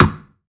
SNARE 120.wav